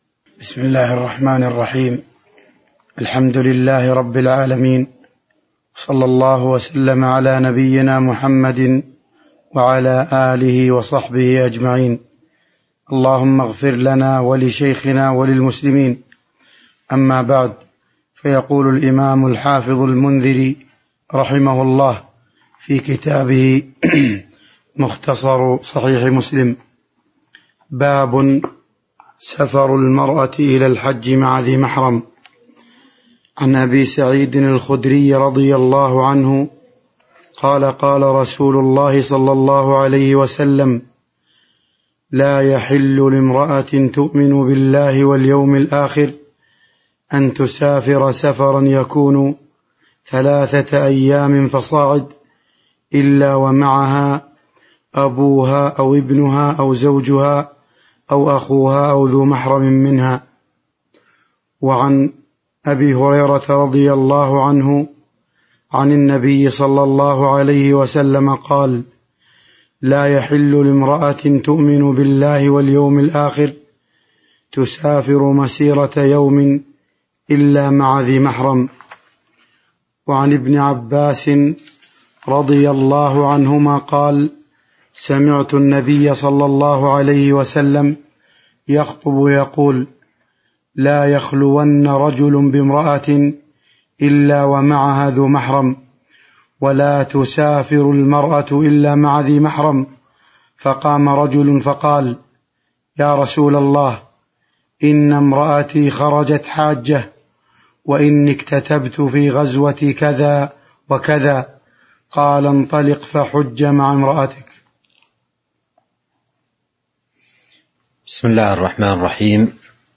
تاريخ النشر ٢٥ محرم ١٤٤٢ هـ المكان: المسجد النبوي الشيخ